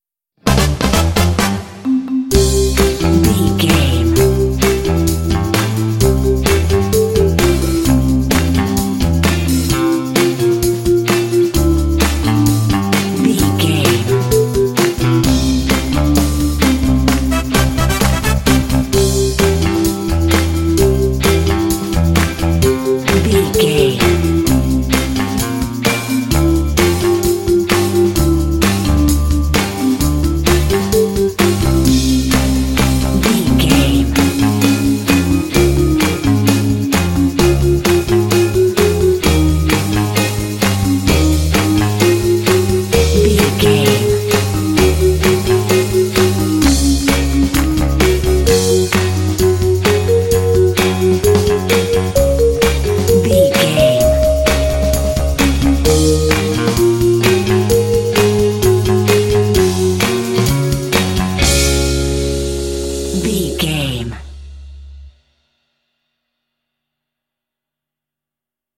Ionian/Major
energetic
playful
lively
cheerful/happy
piano
trumpet
electric guitar
brass
percussion
bass guitar
drums
classic rock